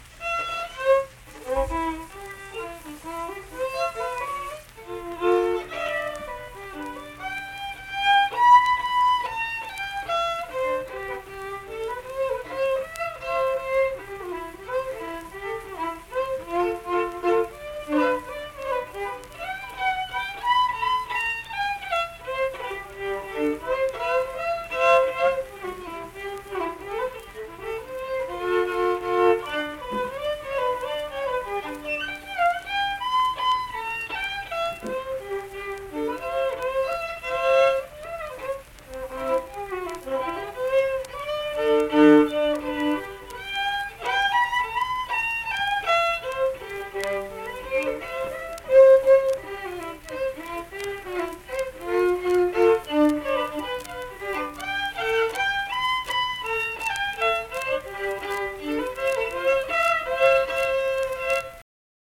Unaccompanied fiddle performance
Instrumental Music
Fiddle